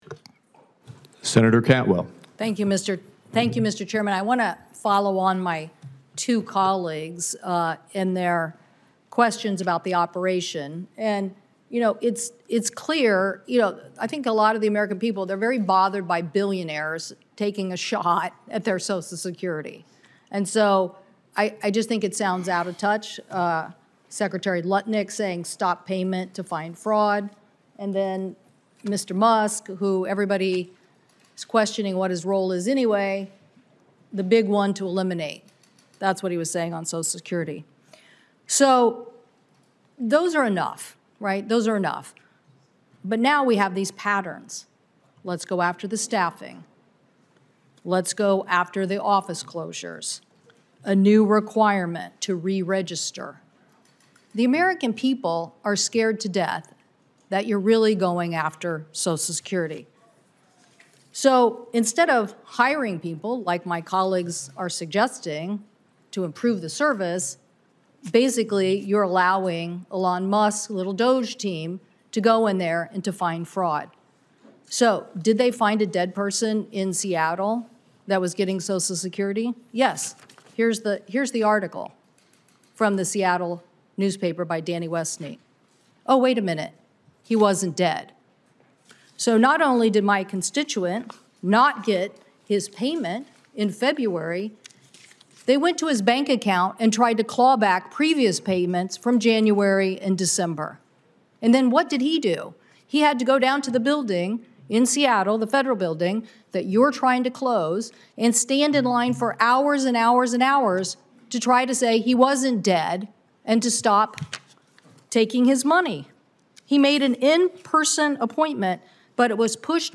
WASHINGTON, D.C. – Today, U.S. Senator Maria Cantwell (D-WA), senior member of the Senate Finance Committee and ranking member of the Senate Committee on Commerce, Science, and Transportation, pressed Frank Bisignano, President Donald Trump’s pick to serve as Commissioner of the Social Security Administration, on recent comments by Trump officials attacking Americans’ Social Security benefits.